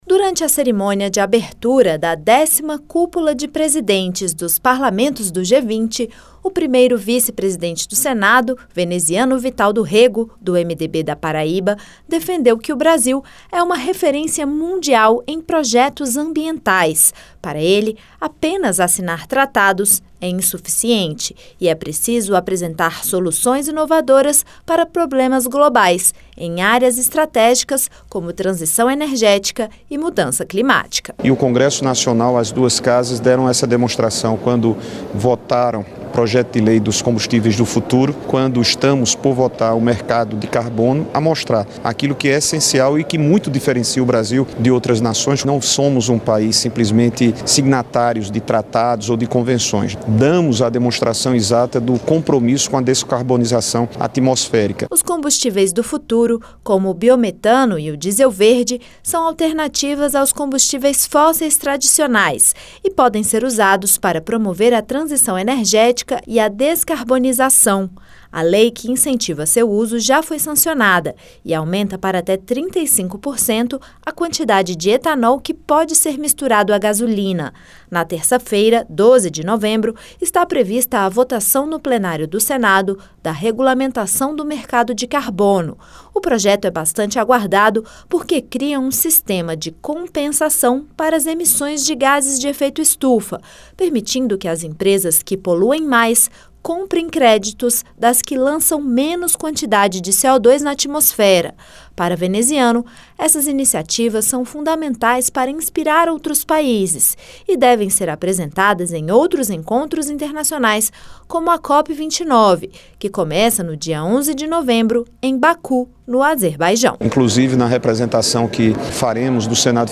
Cúpula de Presidentes dos Parlamentos do G20